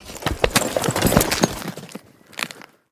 Ledge Climbing Sounds Redone